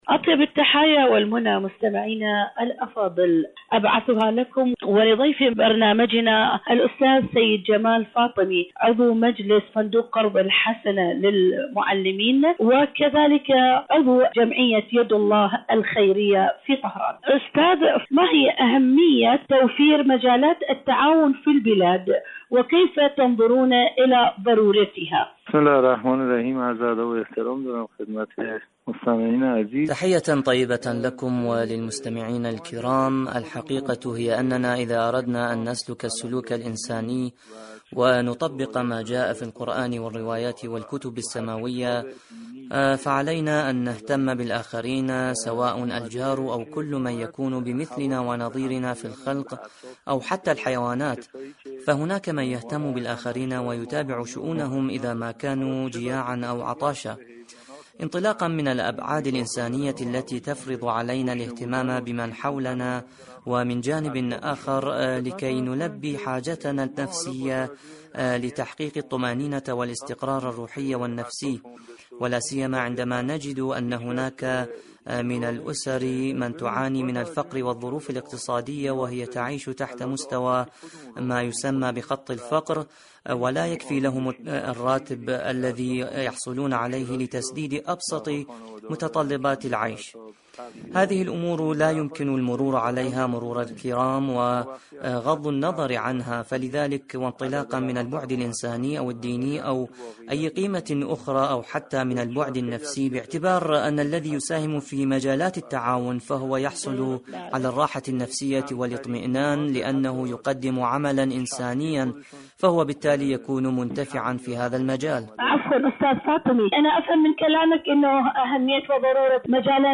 إذاعة طهران-ألوان ثقافية: مقابلة إذاعية